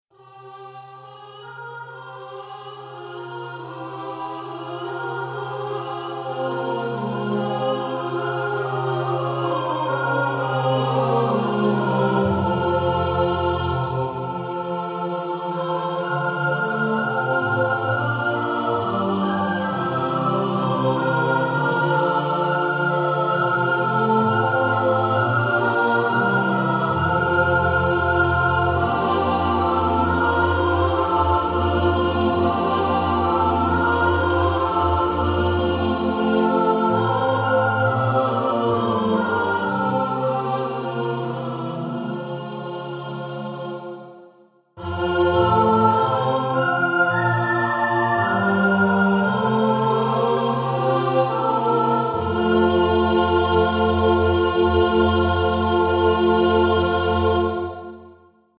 Choir Example 1